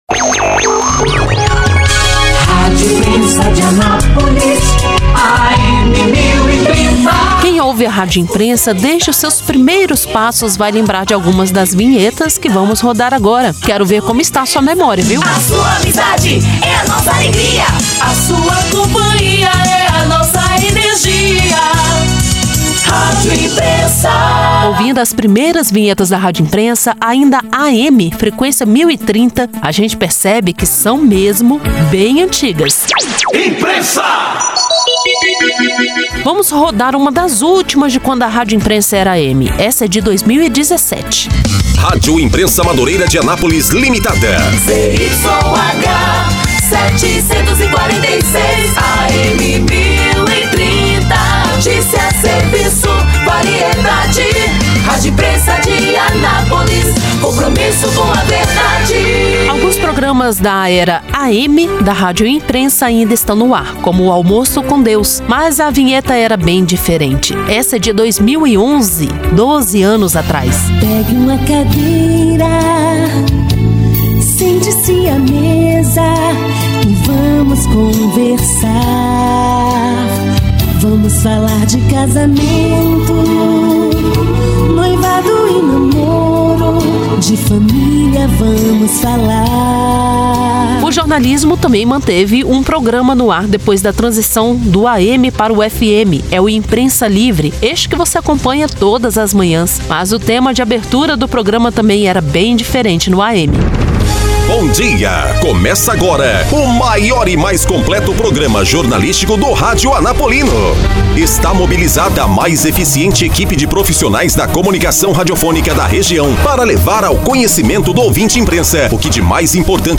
Reportagem
Para apresentar as mudanças, a equipe de jornalismo da Rádio Imprensa preparou uma reportagem especial com apresentação das novas vinhetas. O material destaca ainda as vinhetas mais antigas da emissora.